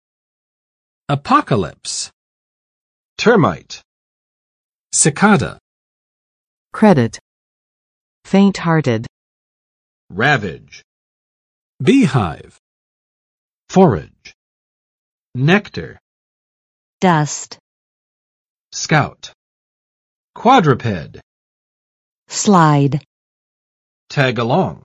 [əˋpɑkə͵lɪps] n. 世界末日